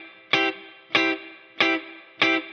DD_TeleChop_95-Cmaj.wav